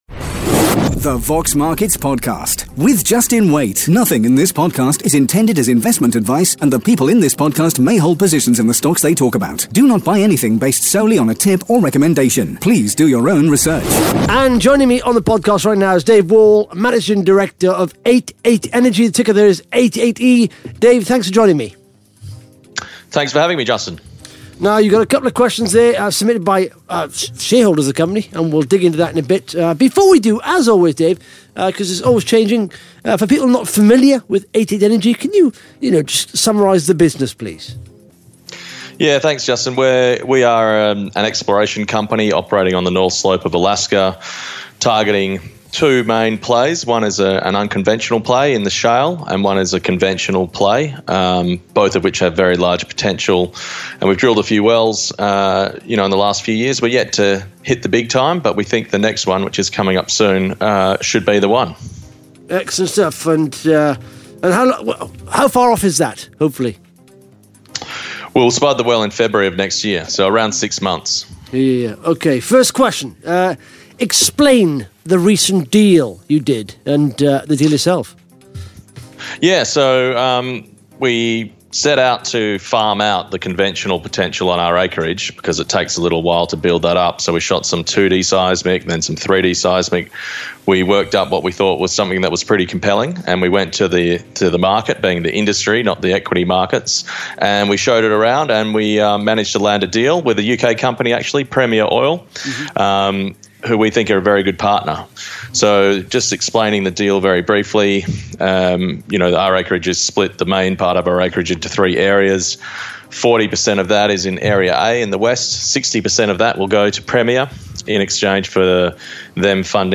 answers shareholder questions